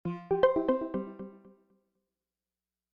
Звук сигнала Alarm04